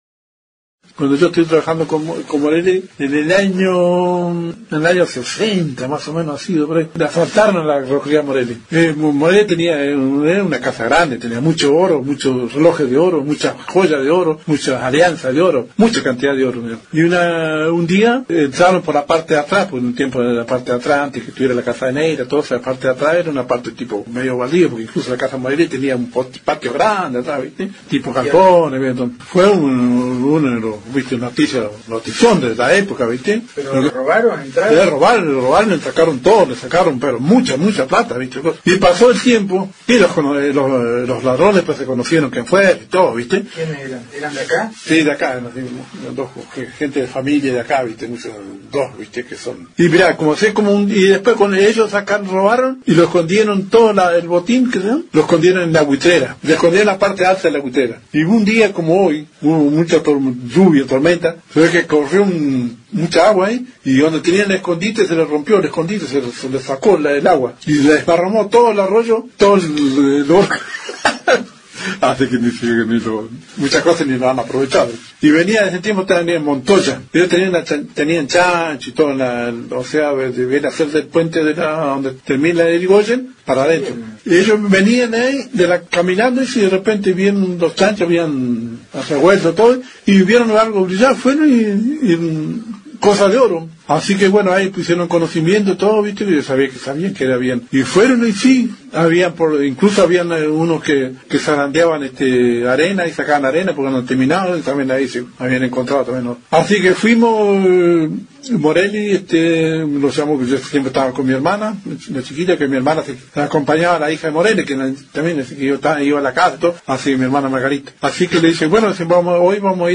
en una charla que tuvo con Noticias de Esquel